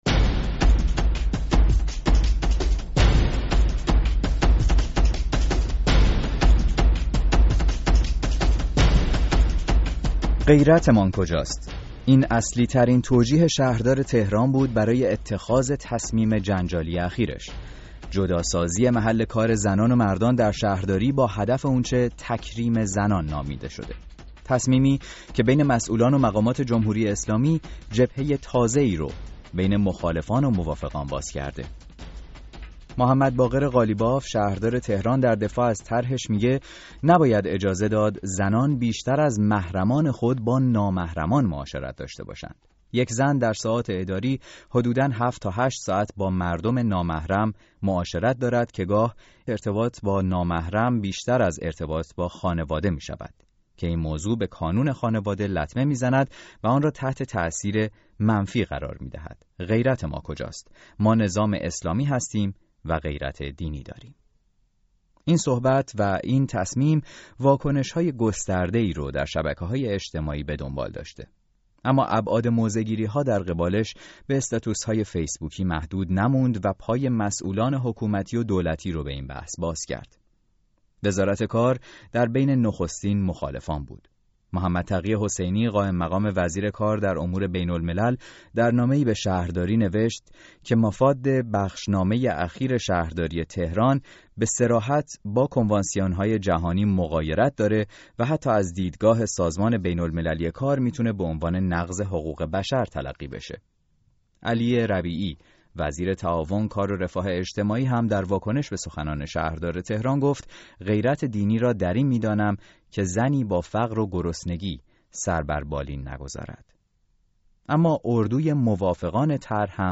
مخاطبان رادیو فردا بود تا به موضوع تفکیک جنسیتی، امکان عملی شدنش و تاثیر آن بر جامعه ایرانی بپردازد.